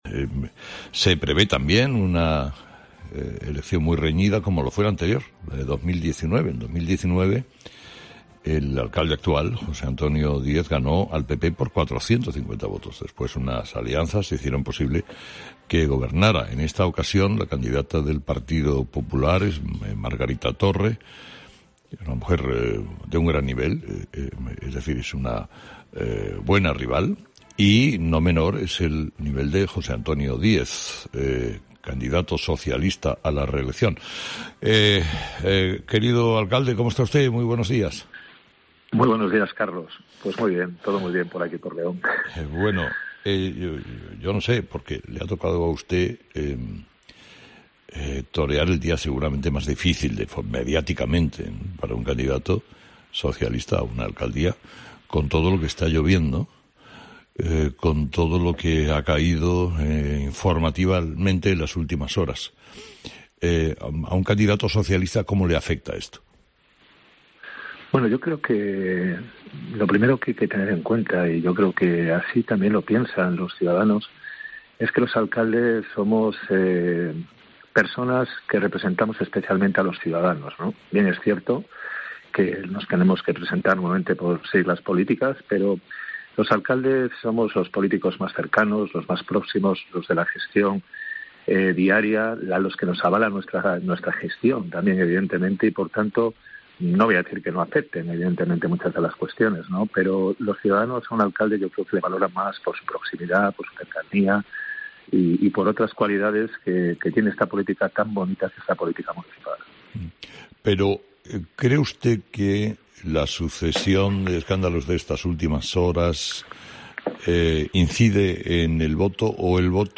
Carlos Herrera entrevista a Jose Antonio Diez, alcalde de León, en el día de cierre de la campaña electoral